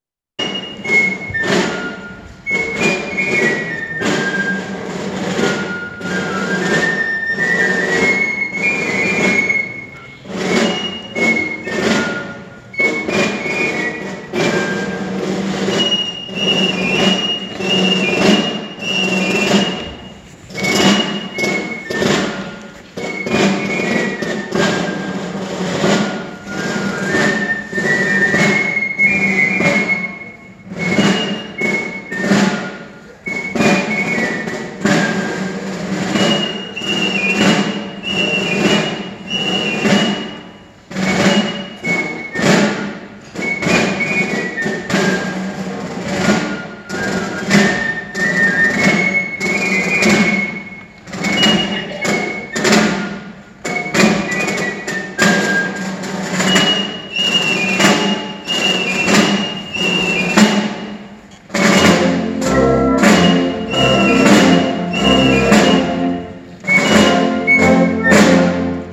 dos tambores y un pífano
marcha